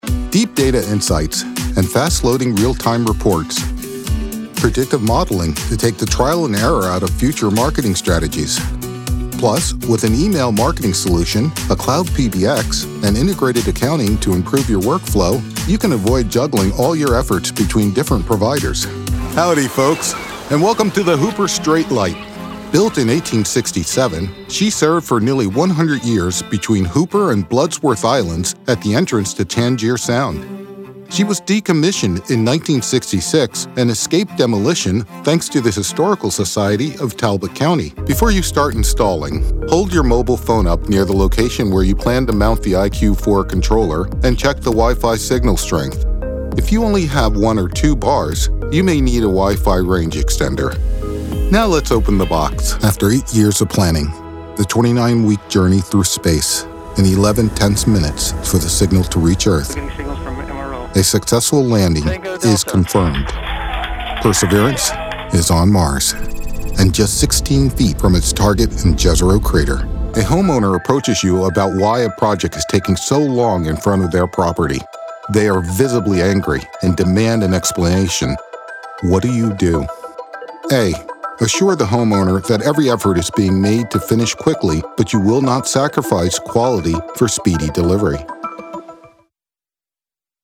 My voice is Authoritative, Gritty, Conversational, Commanding, Deep, Trustworthy.
Narration
Southern accent
Middle Aged